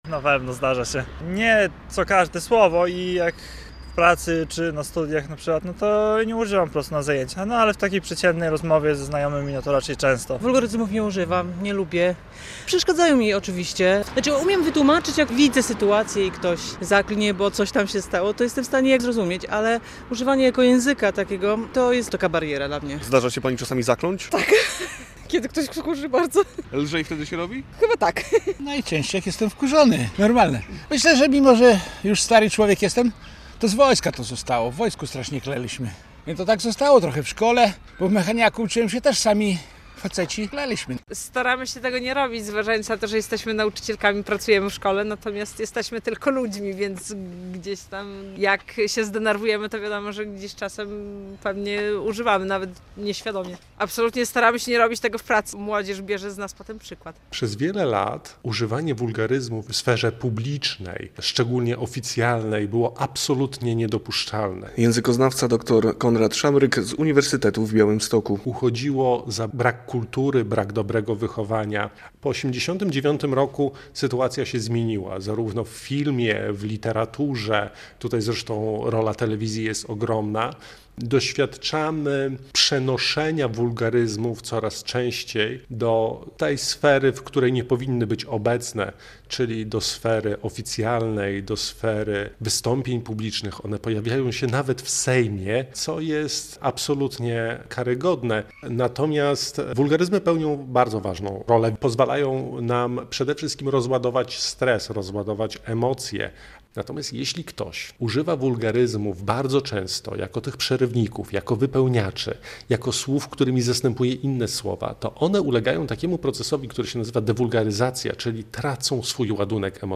relacja
Zapytani przez nas białostoczanie, przyznają, że nie stronią od wulgaryzmów, ale starają się ich nie nadużywać, a najczęściej niecenzuralne wyrazy padają w emocjach.